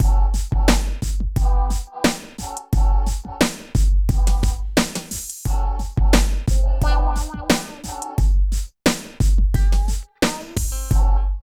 64 LOOP   -R.wav